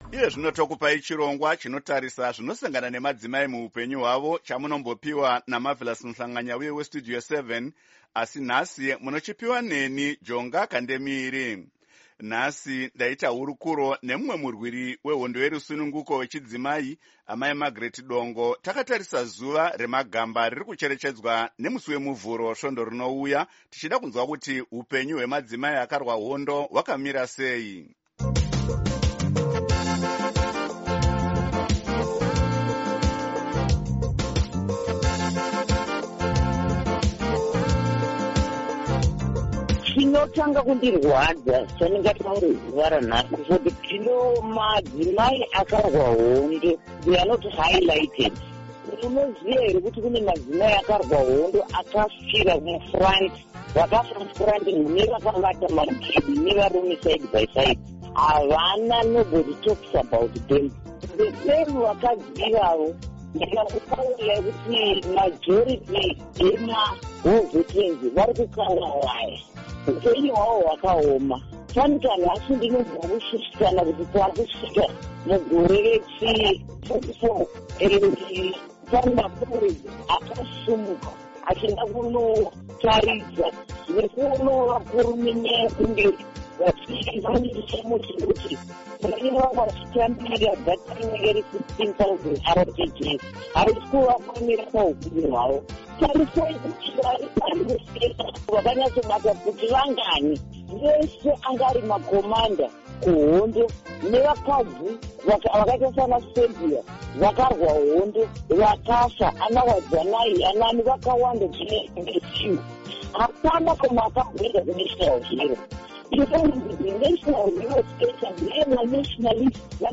Hurukuro naAmai Margaret Dongo